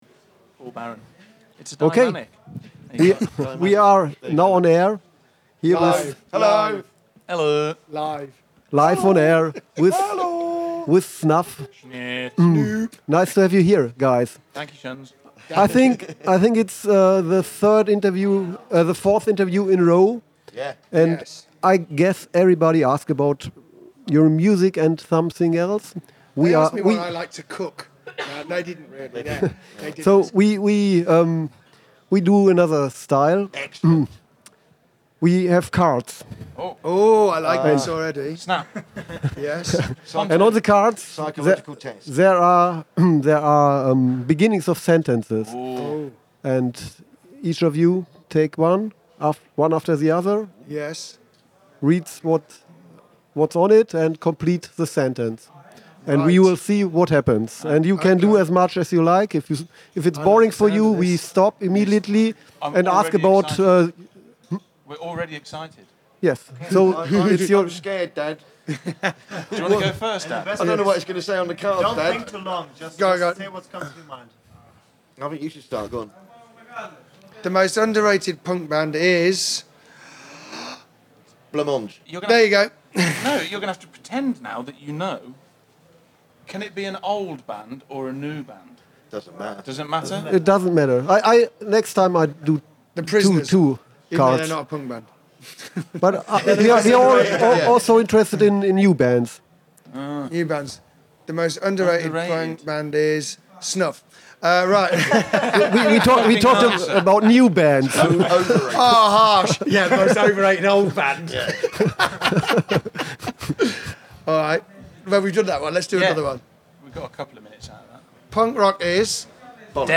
Letzte Episode Interview mit Snuff @ Punk Rock Holiday 10. August 2017 Nächste Episode download Beschreibung Teilen Abonnieren Das wohl lustigste Interview beim Punk Rock Holiday 1.7 haben wir wohl mit Snuff geführt. Leider hatten die Jungs nur zehn Minuten Zeit, dann stand das nächste Interview an.
interview-mit-snuff-punk-rock-holiday-mmp.mp3